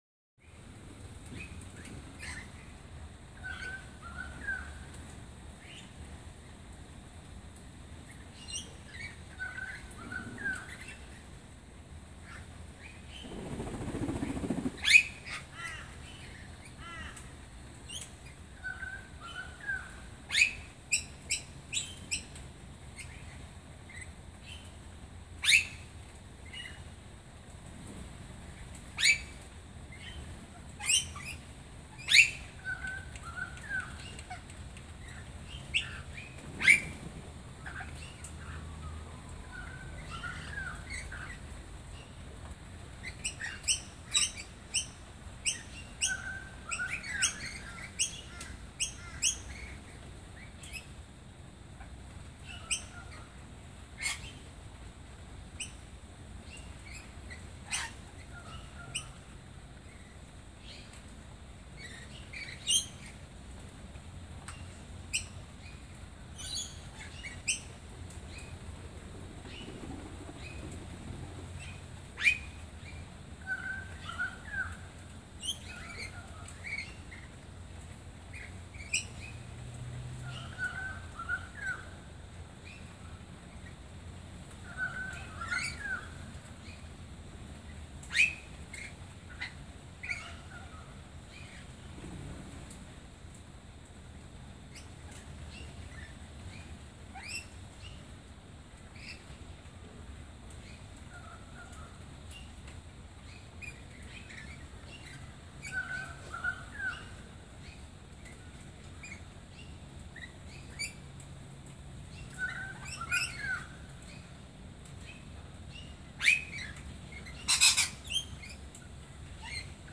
Noisy Miners, Rainbow Lorikeets And A Crested Pigeon Get Spooked By An Alarm Call